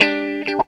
GTR 42 F#M.wav